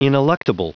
Prononciation du mot ineluctable en anglais (fichier audio)
Prononciation du mot : ineluctable